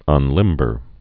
(ŭn-lĭmbər)